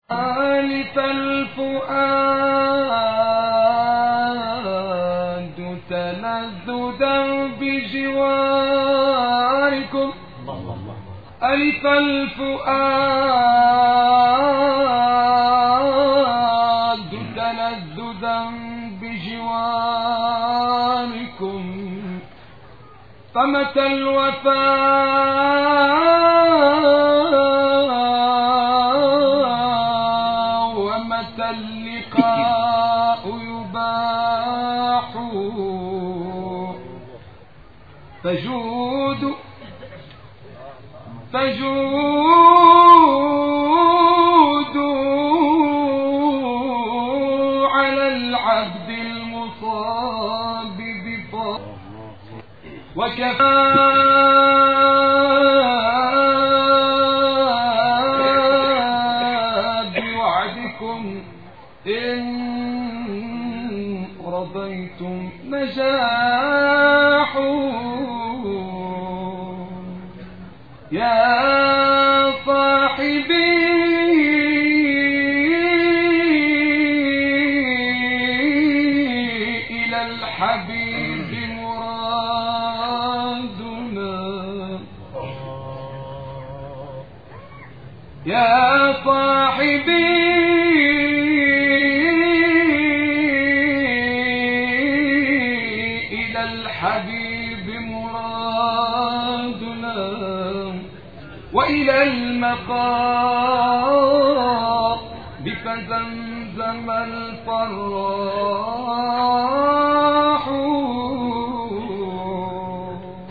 Chants spirituels